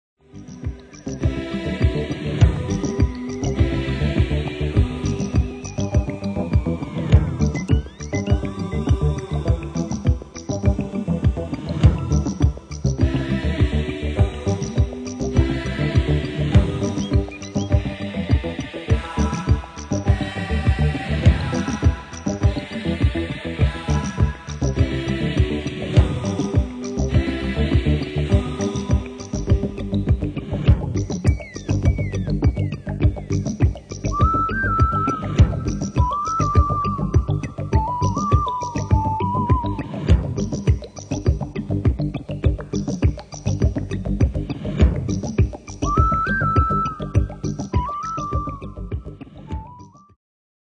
Genere:   Soul | Disco | Funky